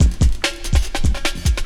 16 LOOP09 -L.wav